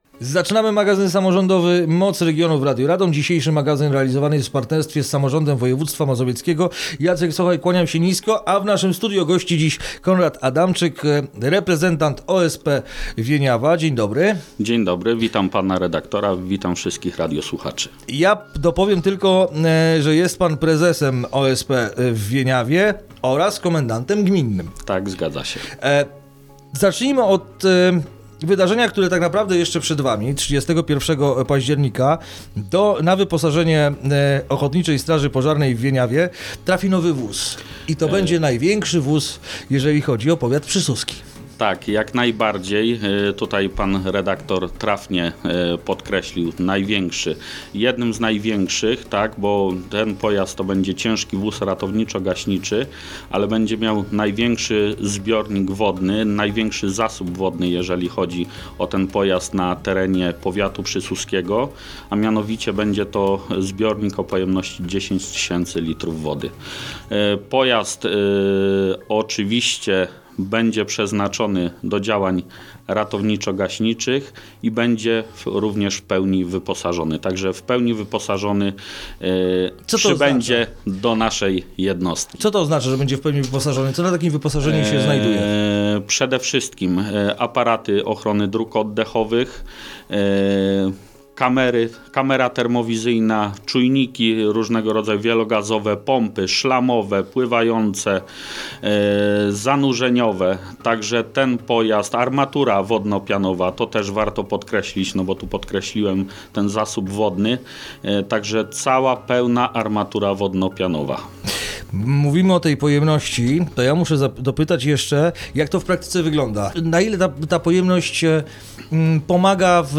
Rozmowa dostępna jest na facebookowym profilu Radia Radom: